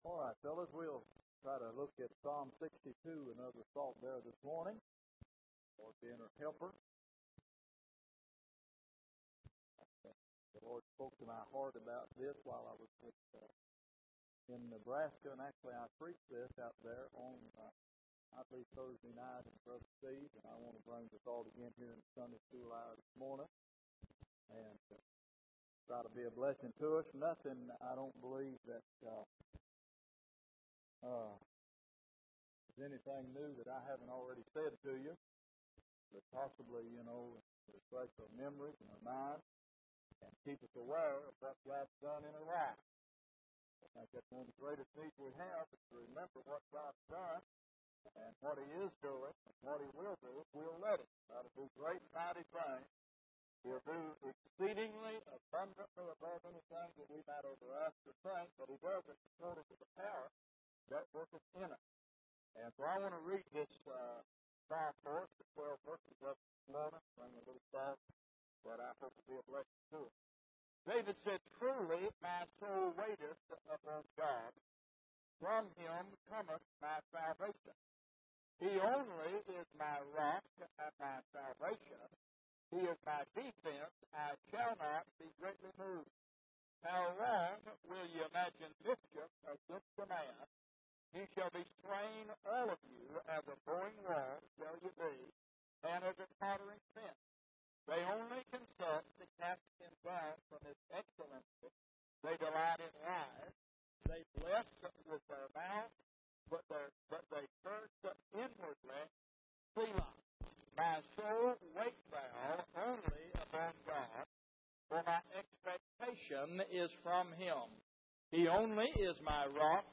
Passage: Psalm 62:1-12 Service: Sunday Morning